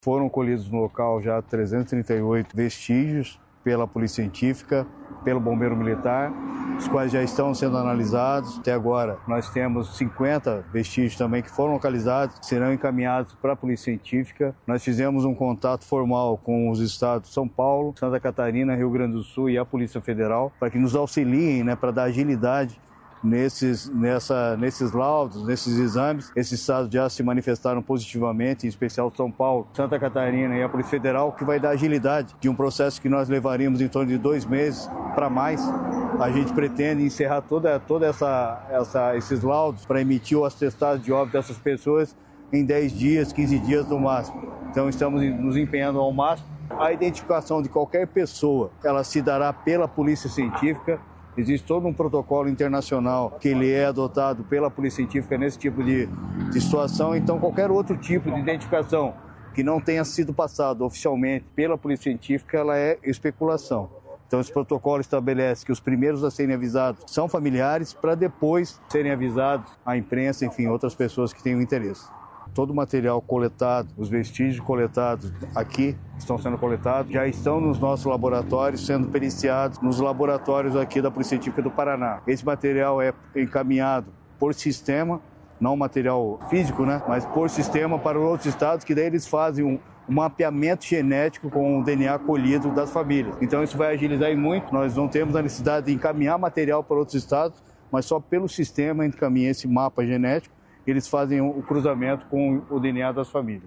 Sonora do secretário da Segurança Pública, Hudson Leôncio Teixeira, sobre as buscas por vestígios da explosão na RMC